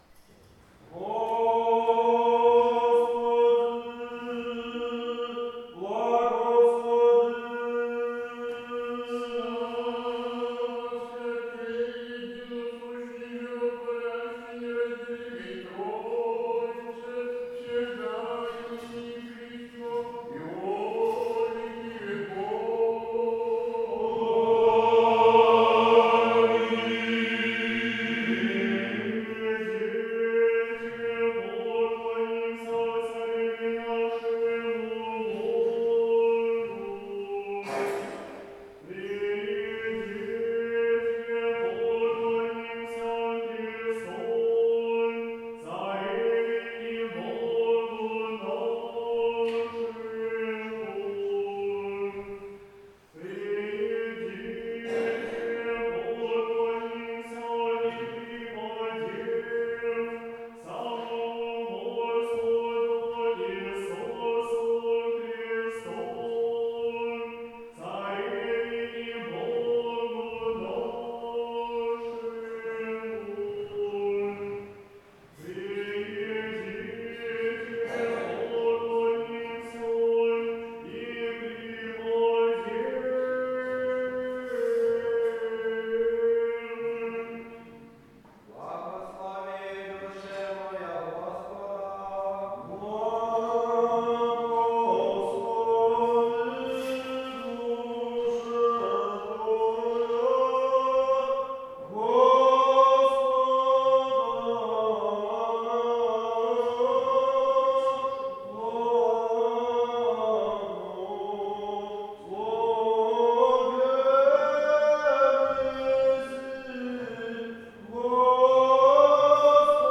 Состав хора достигает 30-ти человек. Правый клирос — мужской.
Левый клирос — смешанный.
Пение хоров отличает строгий унисон, манера звукоизвлечения, приближенная к академической, четкая дикция. Регистр пения стабилен.
01 На всенощном бдении «Господи, благослови», «Приидите, поклонимся», псалом 103 с припевами — фрагмент архиерейского богослужения престольному празднику Рожества Пресвятой Богородицы.